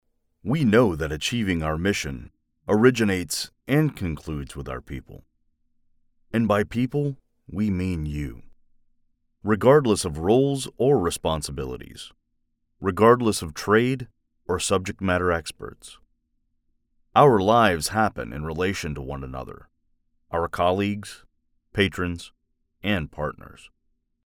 Accents and Dialects
Southern; Neutral; Midwestern